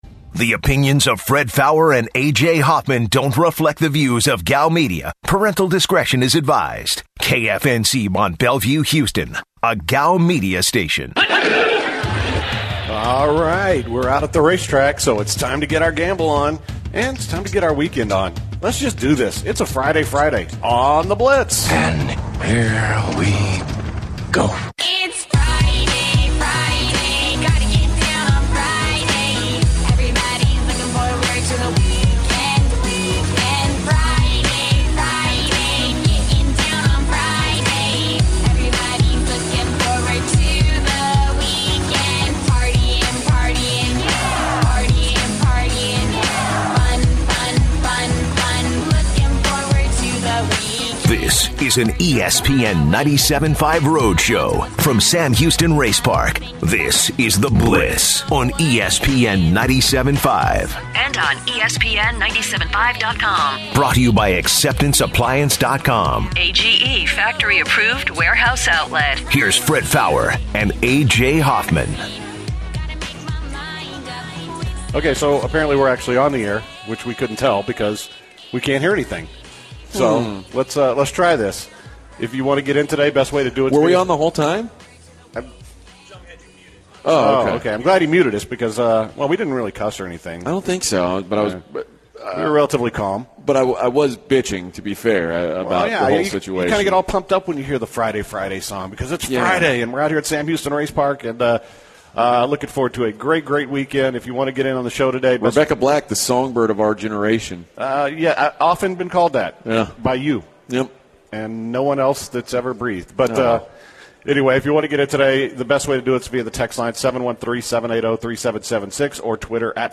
begin today’s show live from Sam Houston Race Park